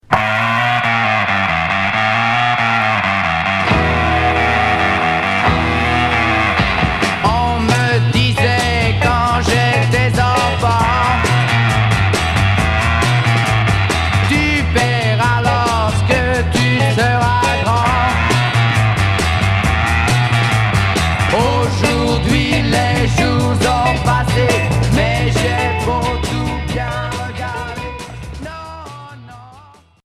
Rock garage